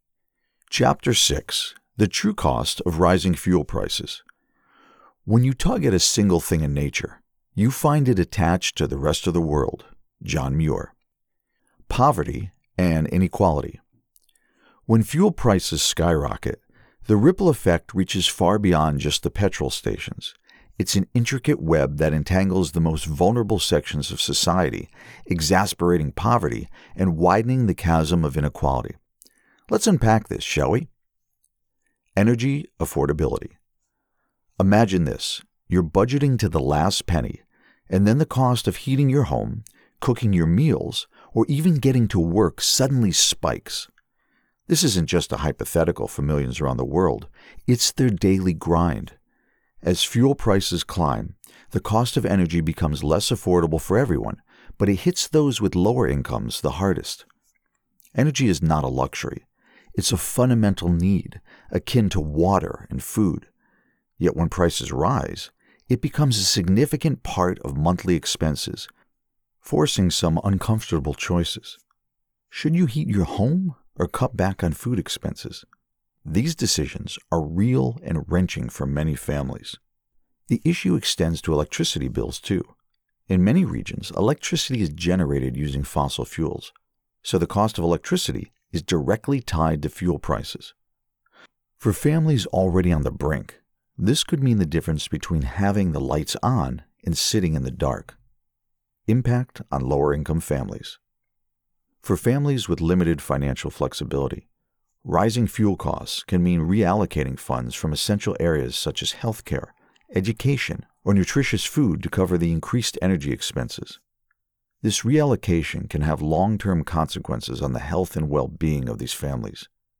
Audiobook - Non Fiction
Middle Aged
My voice has been described as warm, trustworthy, and confident.